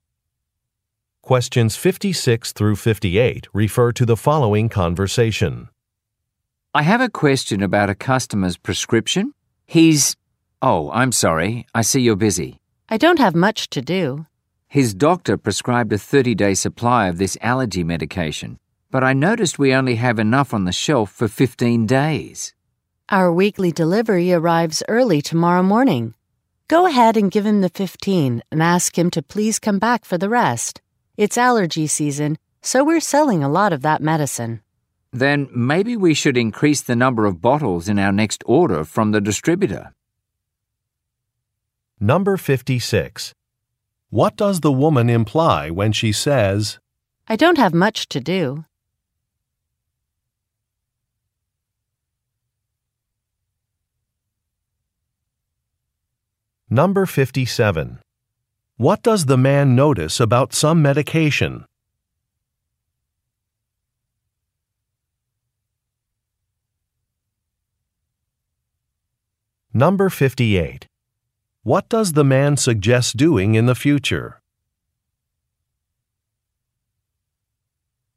Question 56 - 58 refer to following conversation: